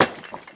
stone_break.ogg